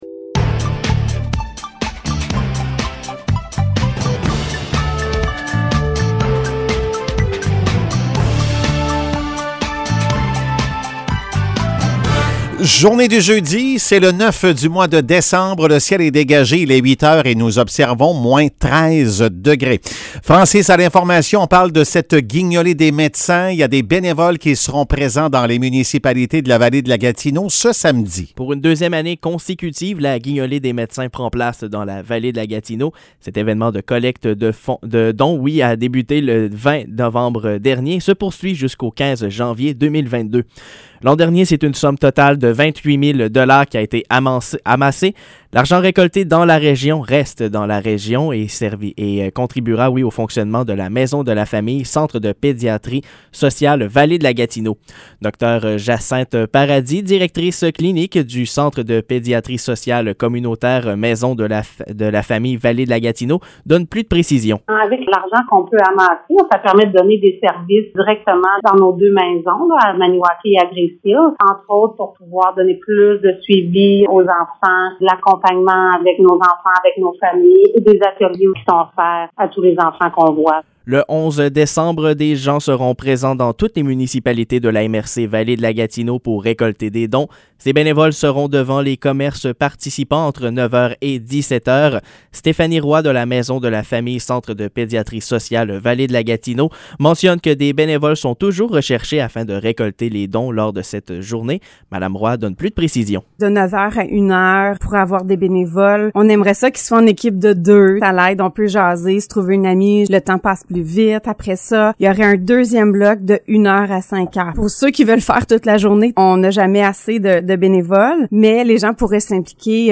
Nouvelles locales - 9 décembre 2021 - 8 h